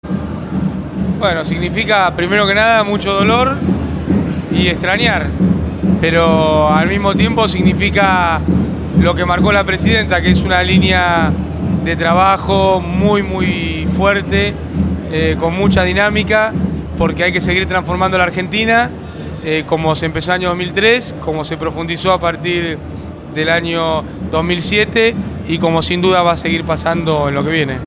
Al cumplirse un mes de su fallecimiento, La Cámpora organizó en la ciudad de La Plata un acto central en homenaje al ex Presidente.